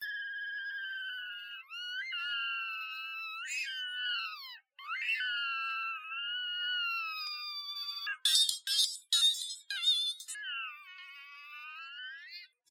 诡异的声音
描述：这个网站的效果汇编，加入了一些效果。嘈杂，讨厌，相当酷
标签： 外星人 冲突 反馈 响亮 混乱 噪音 尖叫 粉碎 怪异
声道立体声